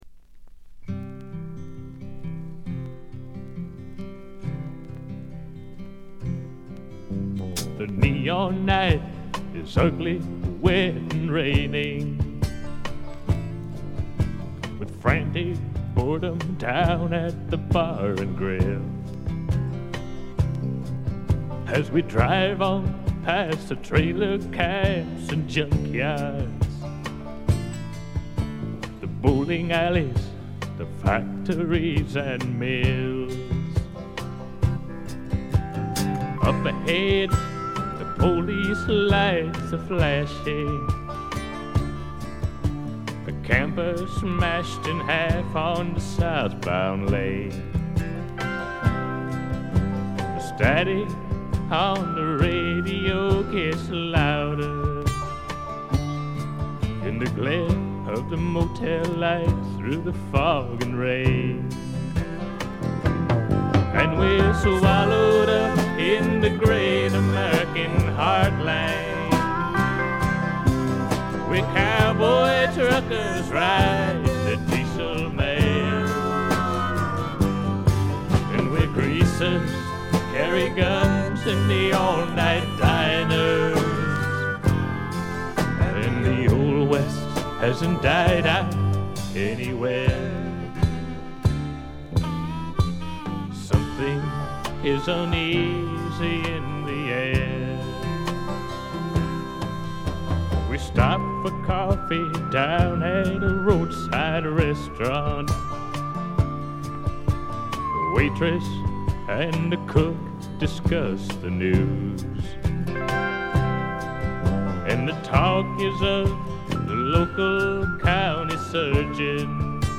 部分試聴ですがごくわずかなノイズ感のみ。
70年代シンガー・ソングライター・ブームが爛熟期を迎え、退廃に向かう寸前に発表されたフォーキーな名作です。
シンガー・ソングライター基本盤。
試聴曲は現品からの取り込み音源です。
Guitar
Piano
Drums
Bass
Background Vocal